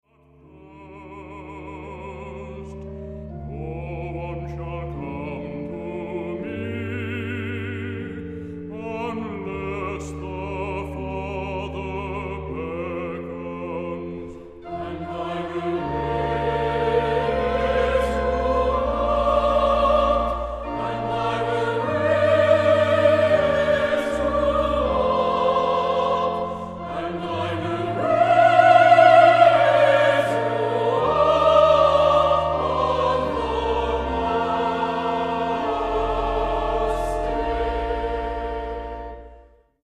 Voicing: Solo